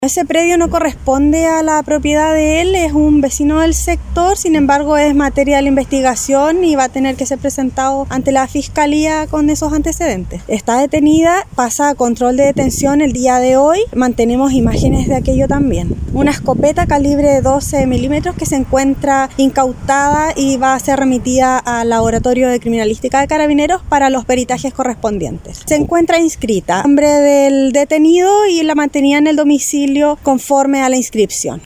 La detención se llevó a cabo sin mayores incidentes, y la persona fue trasladada a la comisaría para pasar a su respectivo control de detención, según lo indicó la oficial: